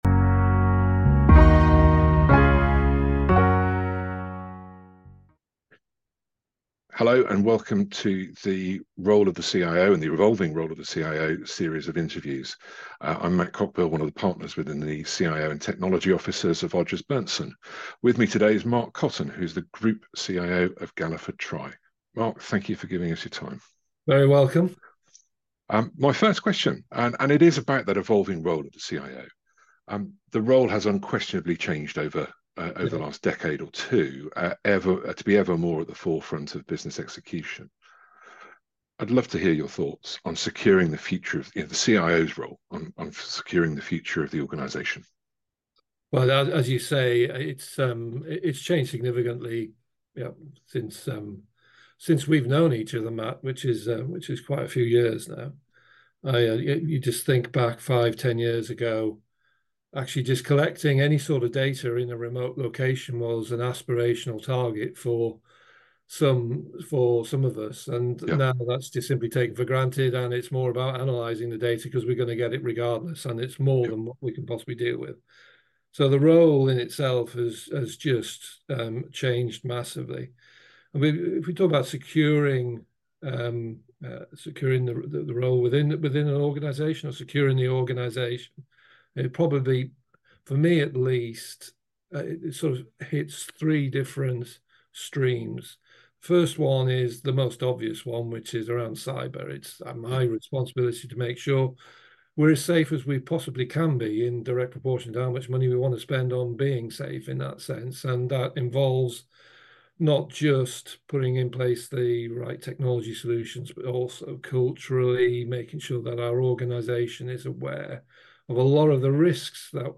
Alternatively, listen to a podcast of the interview here: